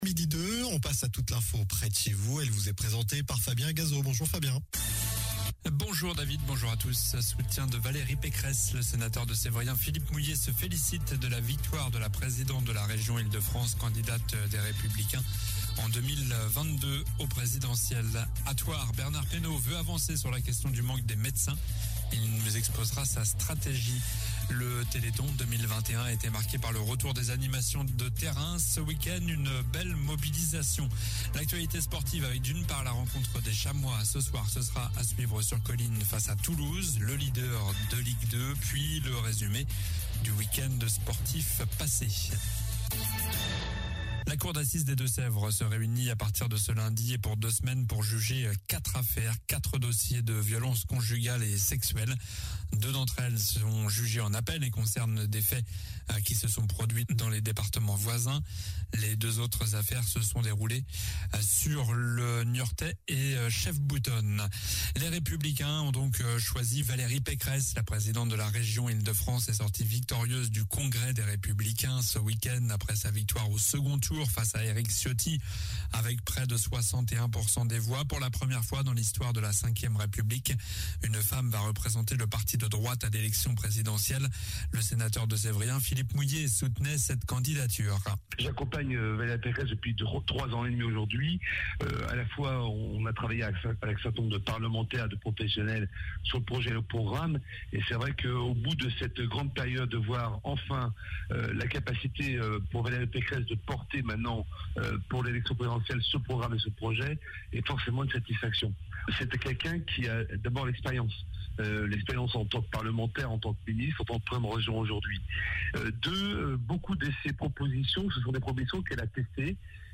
Journal du lundi 6 décembre (midi)